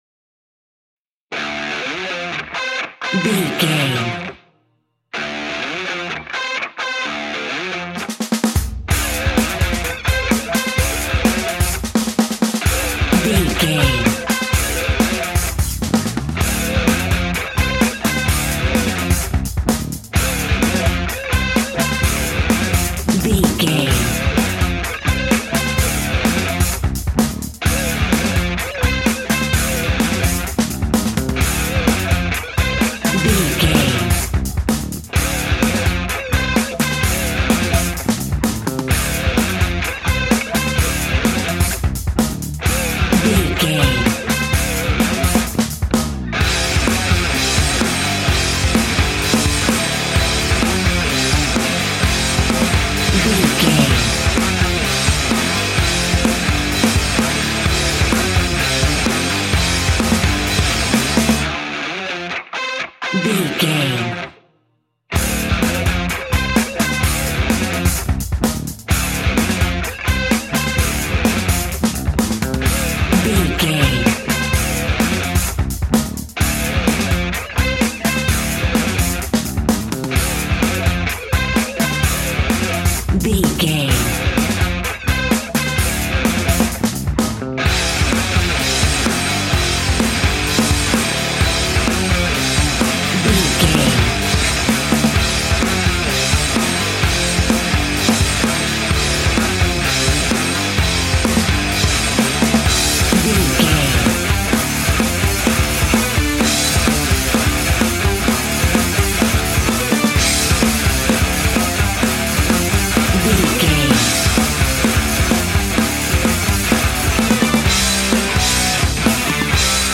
Aeolian/Minor
hard rock
heavy metal
dirty rock
scary rock
rock instrumentals
Heavy Metal Guitars
Metal Drums
Heavy Bass Guitars